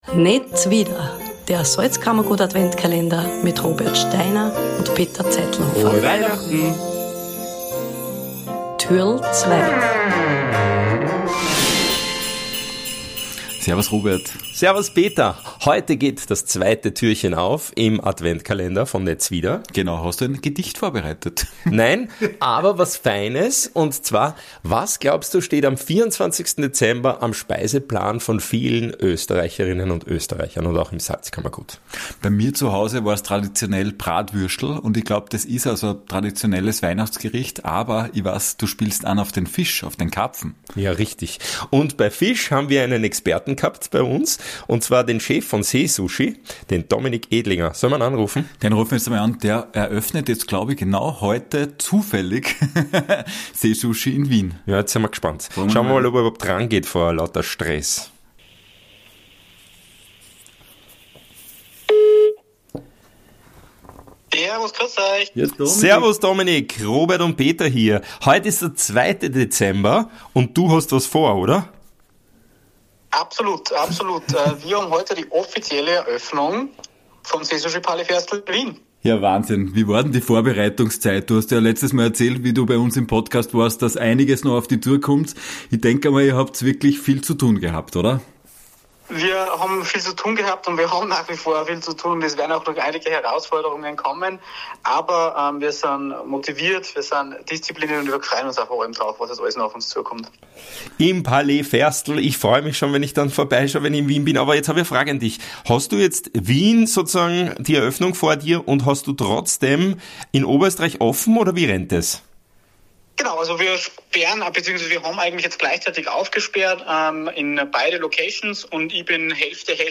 Türchen 2 - Ein Anruf